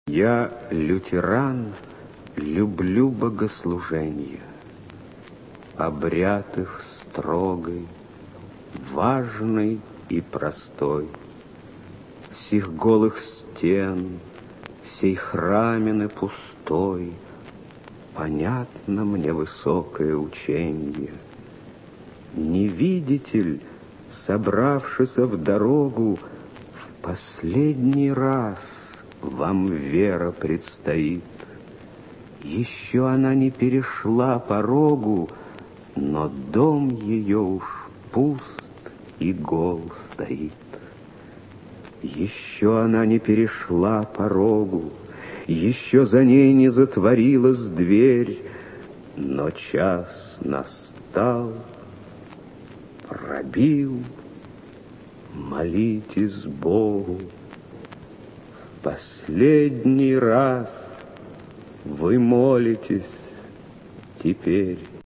Стихотворение Тютчева «Я лютеран люблю богослуженье…»